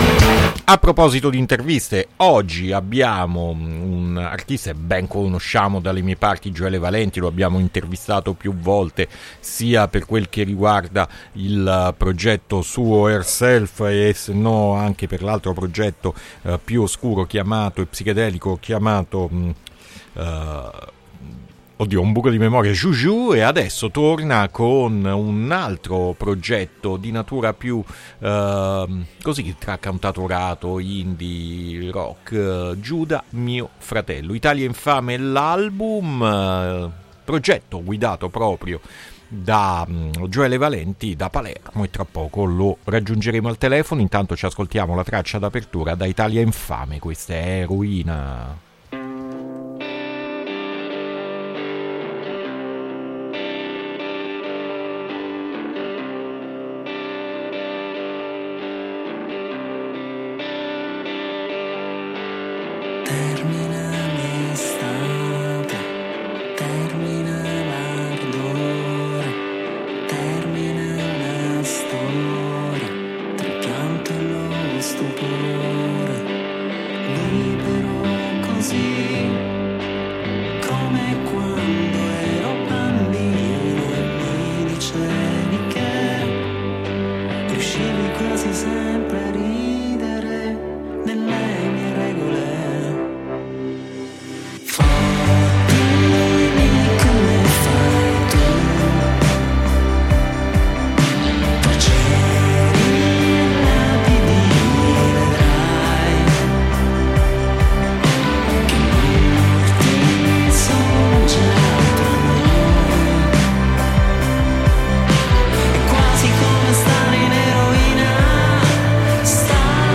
INTERVISTA GIUDA MIO FRATELLO AD ALTERNITALIA 6-3-2026